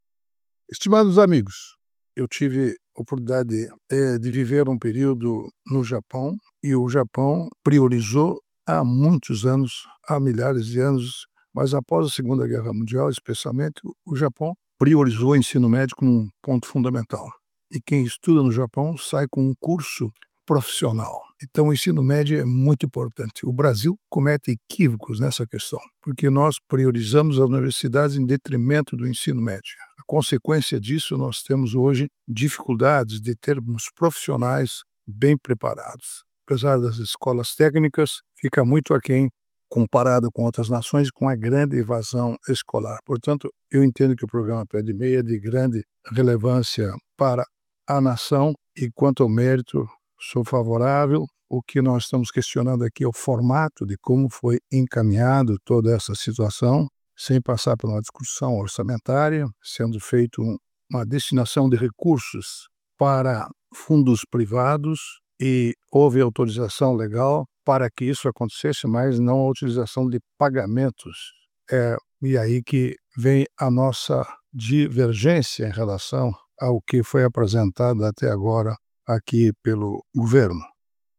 Comentário de Augusto Nardes, ministro TCU.